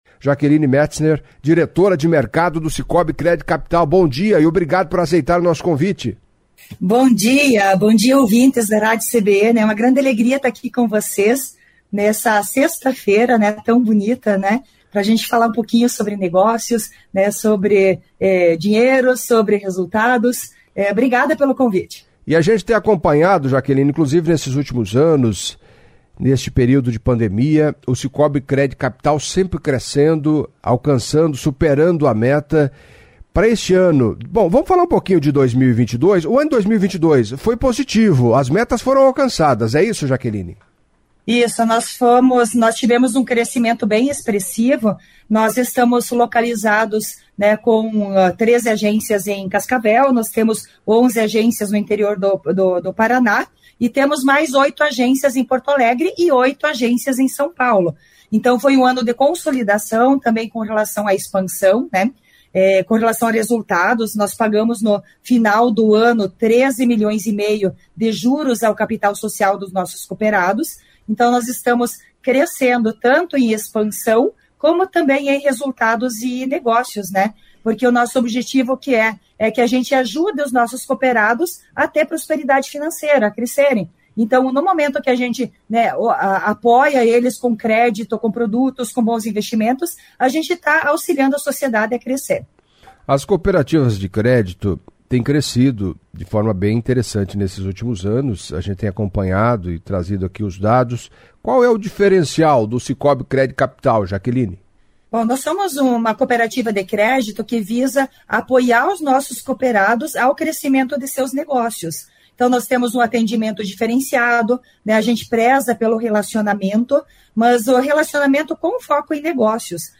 Em entrevista à CBN Cascavel nesta sexta-feira
repondeu dúvidas de ouvintes